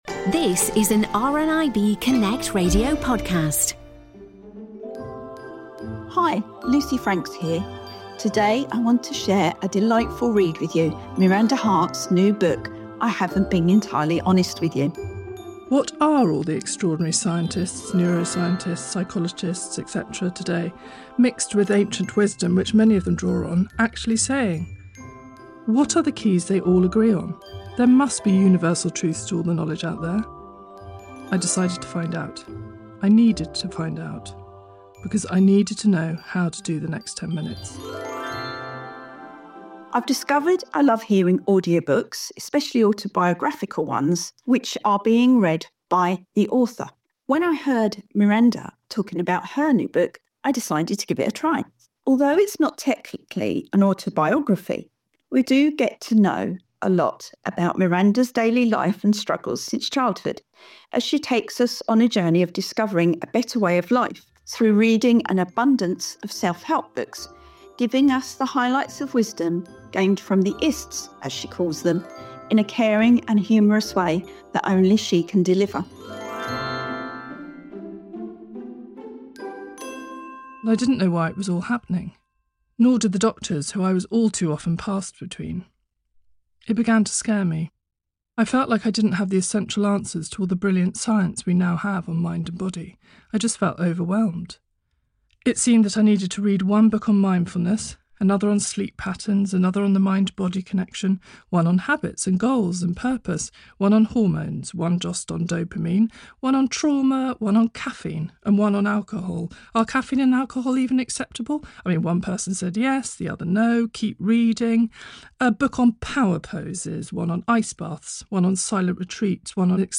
For part 2 of our festive special, we have a lovely book review for you.